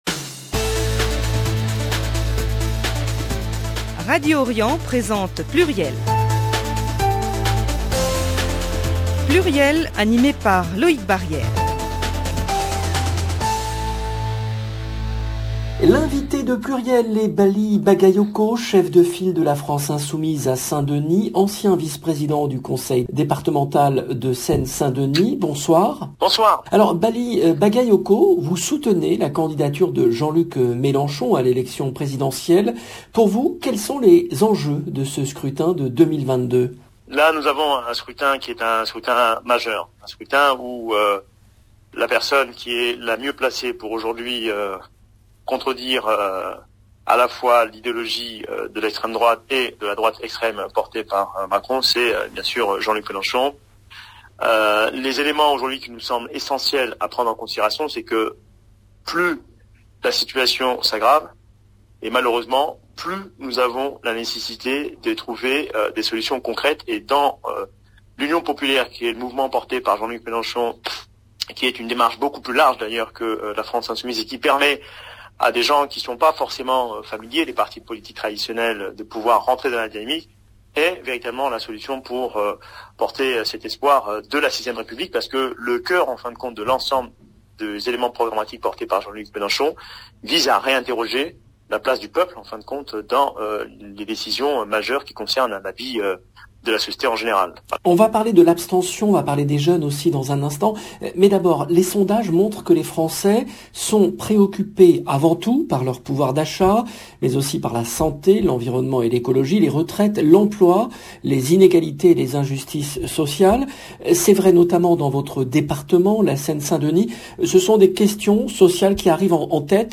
PLURIEL, émission diffusée le lundi 25 octobre 2021
L’invité de PLURIEL est BALLY BAGAYOKO , chef de file de la France Insoumise à Saint-Denis, ancien vice-président du Conseil départemental de Seine-Saint-Denis.